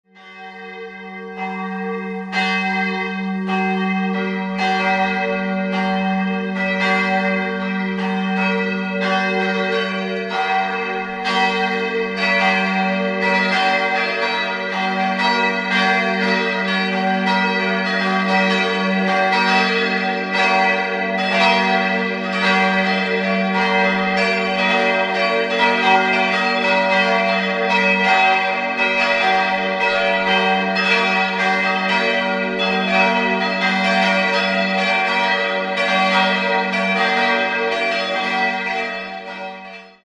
4-stimmiges Salve-Regina-Geläute: ges'-b'-des''-es''
Glocke 1 ges'+8
b'+8
des''+8
Glocke 4 es''+8 182 kg 66 cm 1948 Albert Junker, Brilon